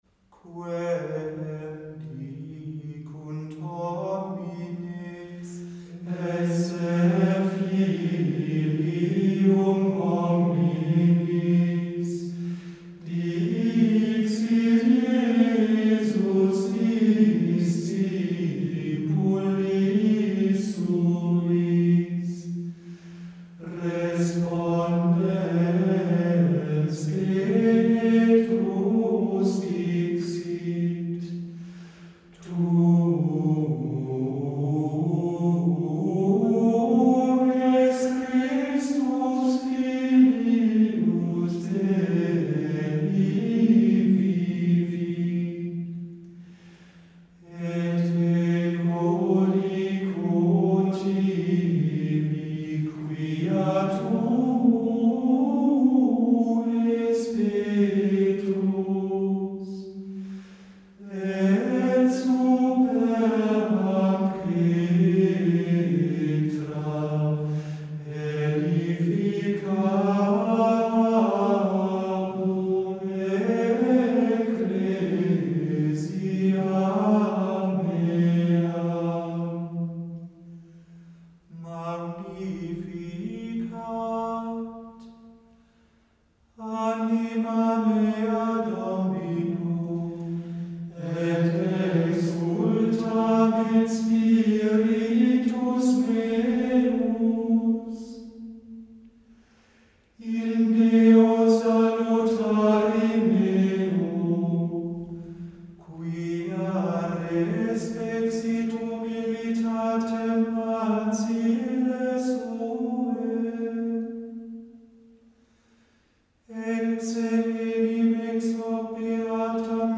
gregorianische Choralschola